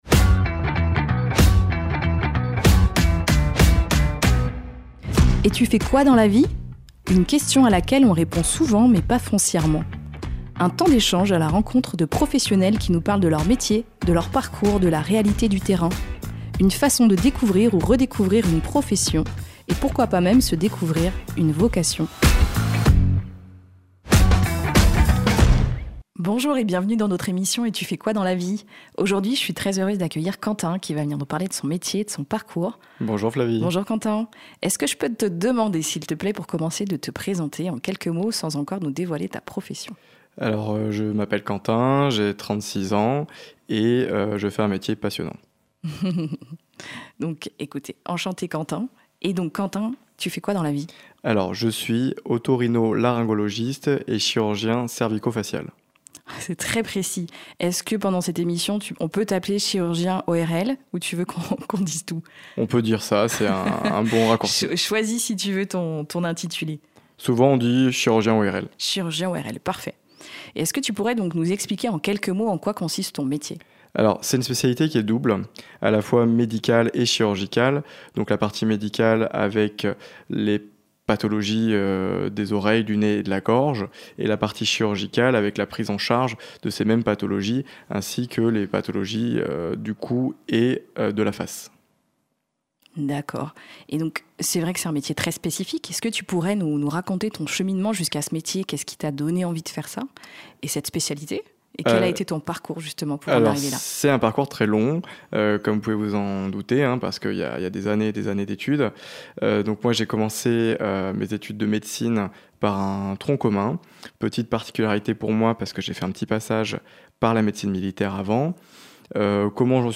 Découvrez des conversations sincères avec des professionnels authentiques qui sauront, qui sait, éveiller chez vous, une ou des vocations.